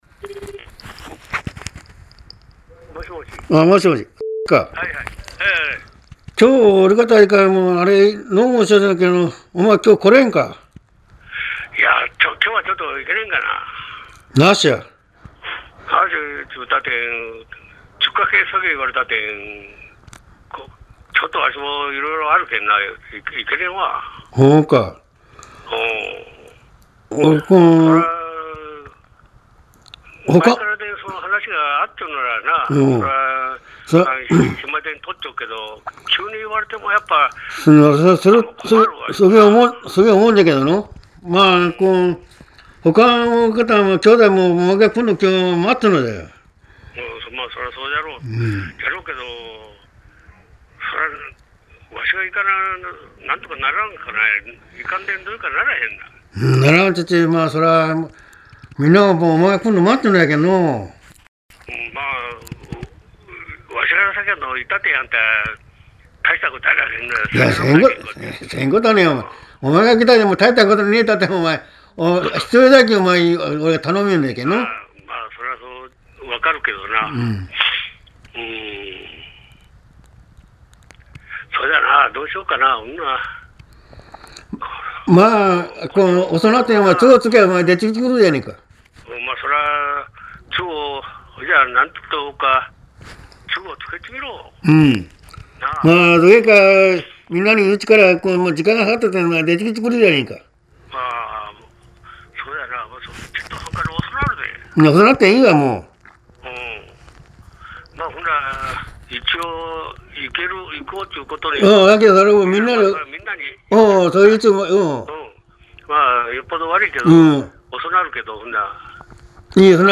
方言ロールプレイ会話データベース ペア入れ替え式ロールプレイ会話＜大分＞
大分高年層男性ペア１